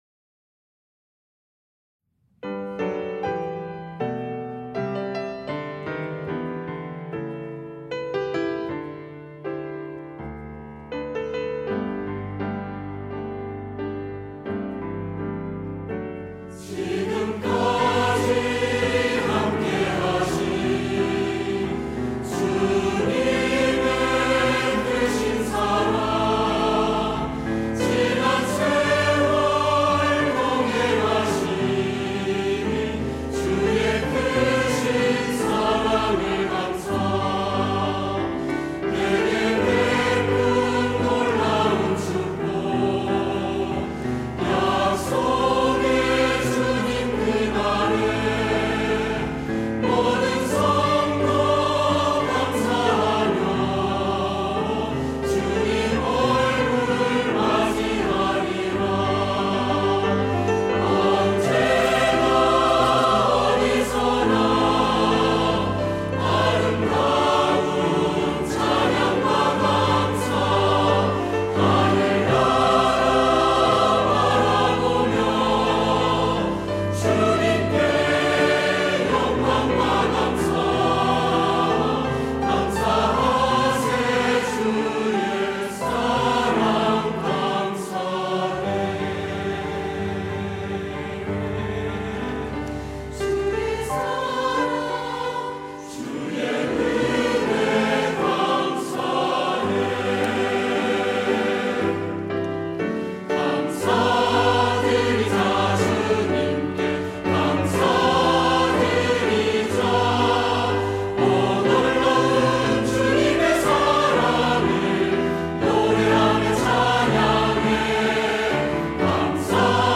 할렐루야(주일2부) - 주의 모든 일에 감사드리며
찬양대